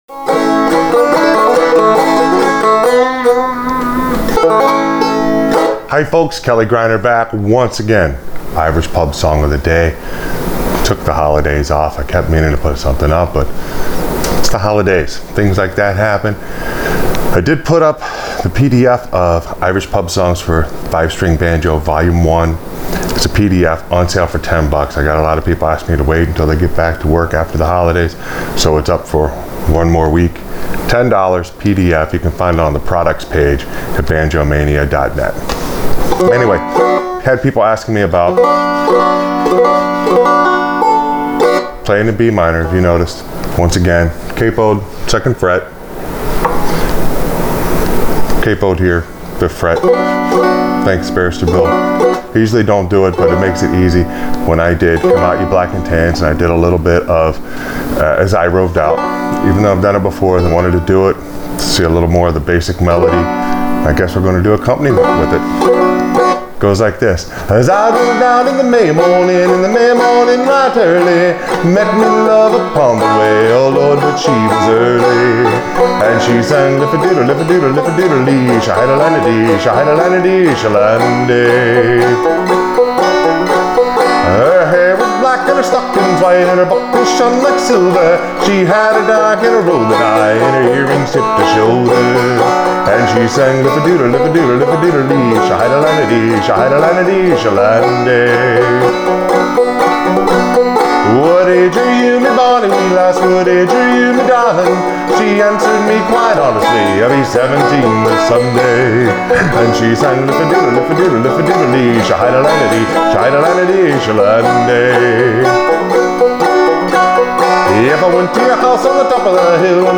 Irish Pub Song Of The Day – As I Roved Out on Frailing Banjo